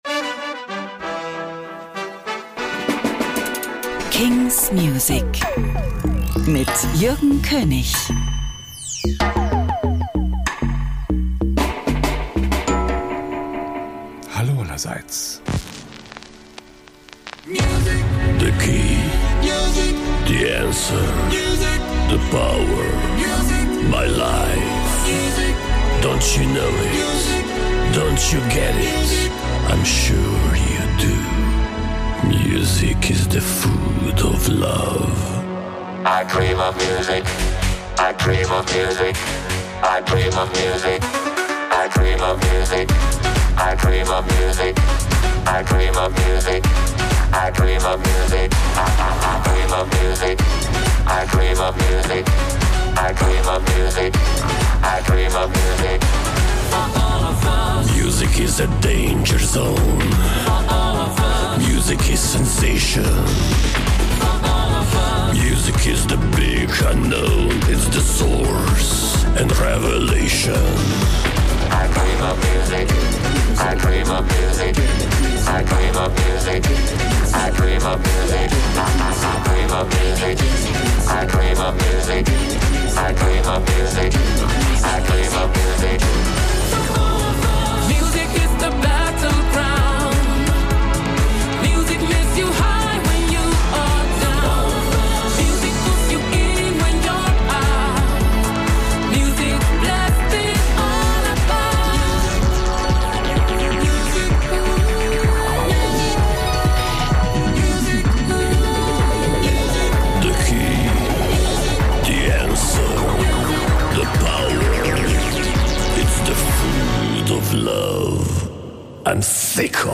brandnew indie & alternative releases